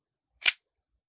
38 SPL Revolver - Close Barrel 001.wav